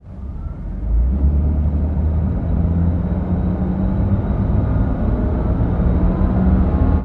Highway / oldcar / start.wav